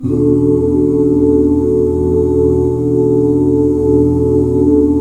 ESUS13 OOO-R.wav